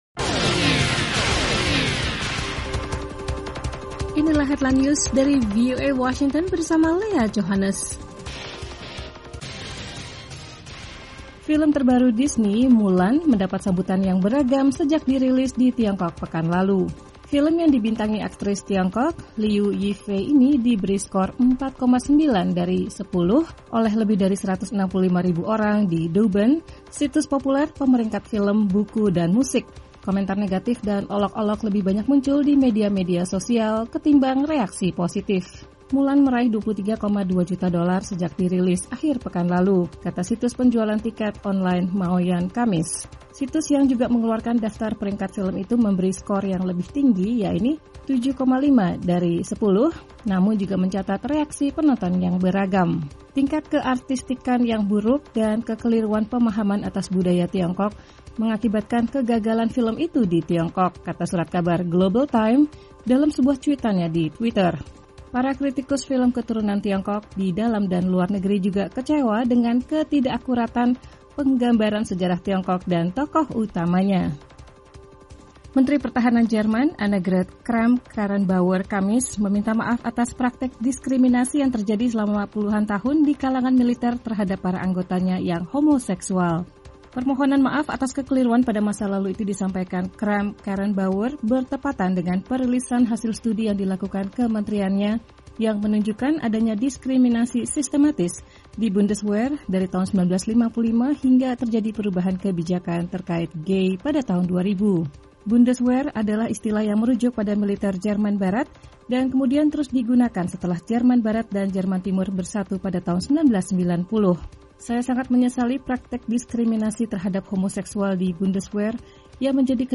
Simak berita terkini langsung dari Washington dalam Headline News, bersama para penyiar VOA, menghadirkan perkembangan terakhir berita-berita internasional.